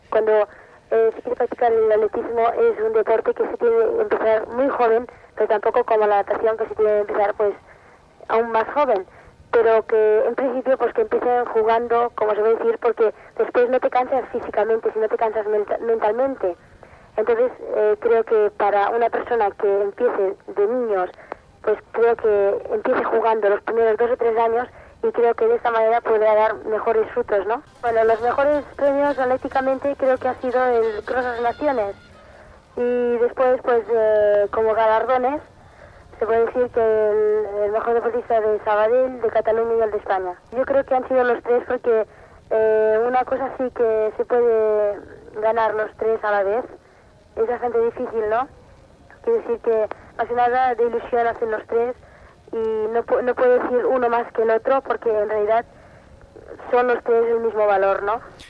Declaracions de l'atleta Carmen Valero
Esportiu